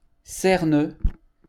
Cerneux (French pronunciation: [sɛʁnø]